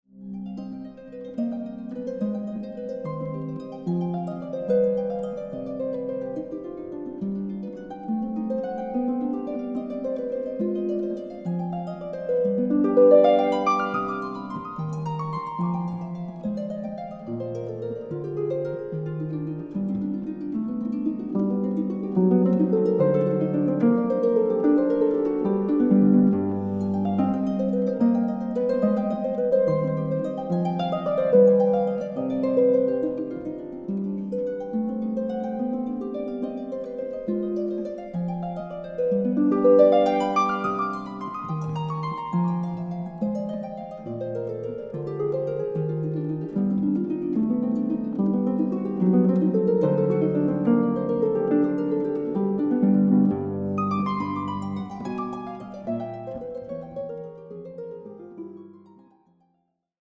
Harfe
Aufnahme: Festeburgkirche Frankfurt, 2024